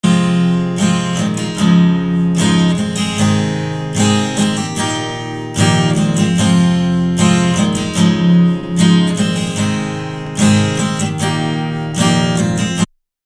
Гул на 175 Гц при воспроизведении записи на колонках
Есть запись акустической гитары.